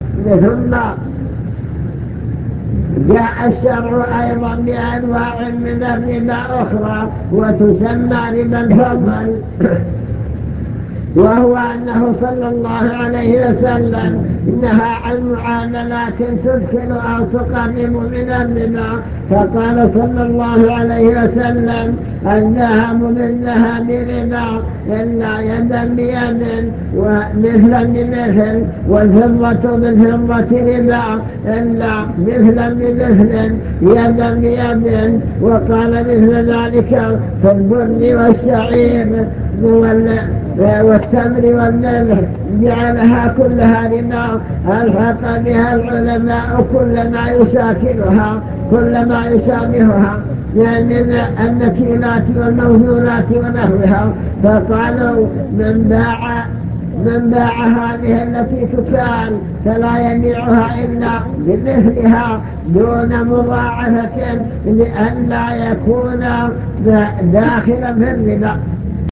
المكتبة الصوتية  تسجيلات - محاضرات ودروس  الربا وما يتعلق به من أحكام الربا